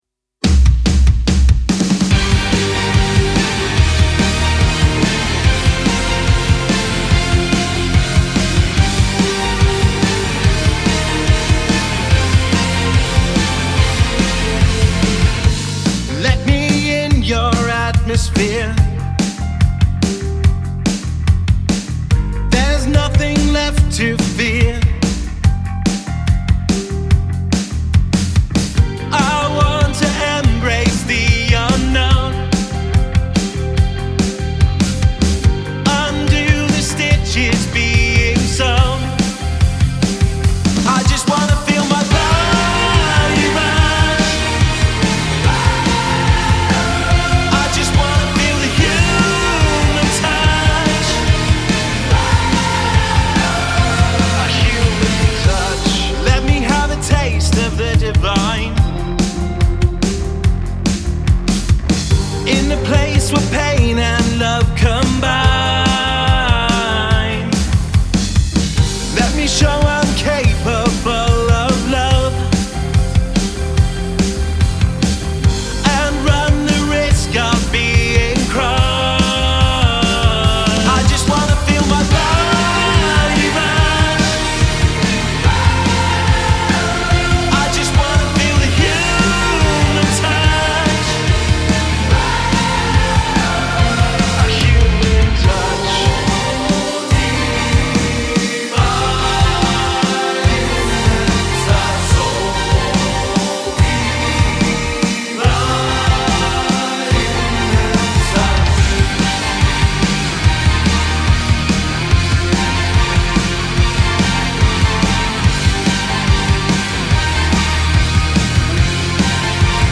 Male Vocal, Electric Guitar, Synth, Bass Guitar, Drums